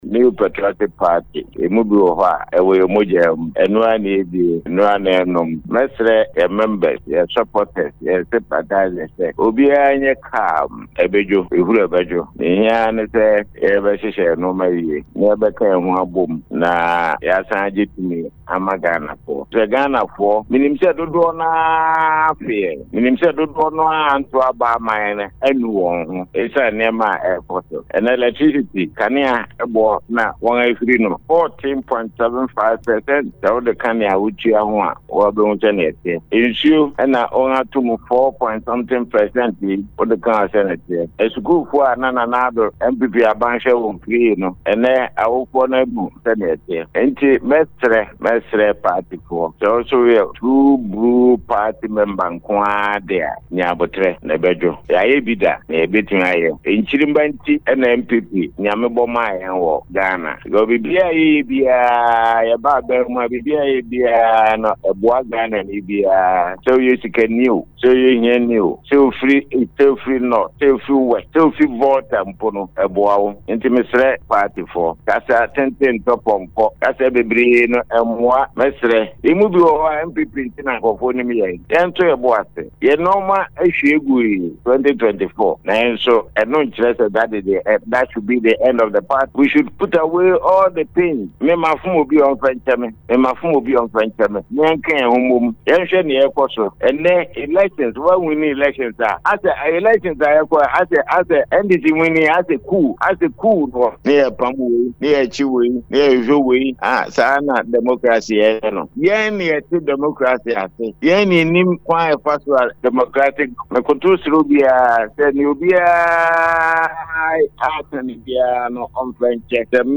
A former Ghana’s Ambassador to South Africa and a Founding Member of the New Patriotic Party (NPP) Ambassador George Ayisi Boateng, has called for total unity among the party’s executives, teeming supporters and sympathizers to recapture political power in 2028.
AMBASSADOR-AYISI-BOATENG-ON-NPP-UNITY.mp3